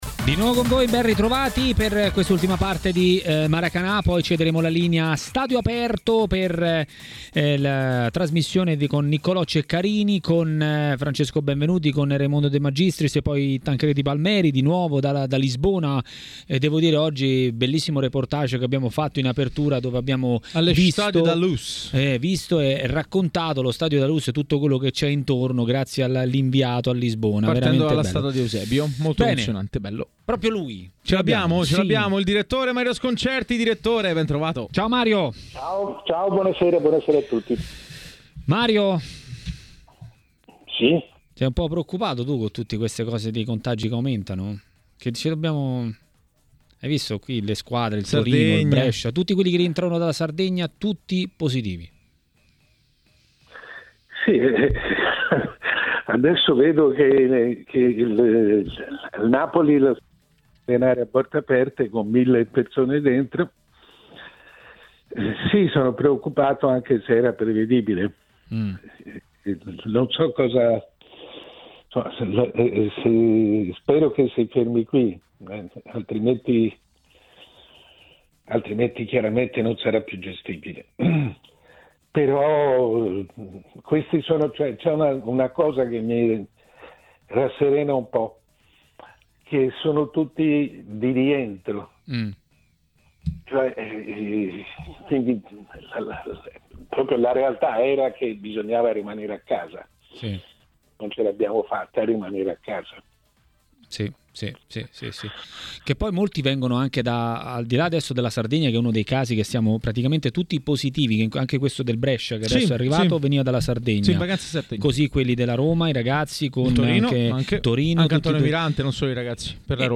Il direttore Mario Sconcerti è intervenuto in diretta ai microfoni di TMW Radio, durante la trasmissione Maracanà. Ecco le sue parole riguardo le due finali europee, Rangnick e la situazione panchina di Inzaghi e Gattuso: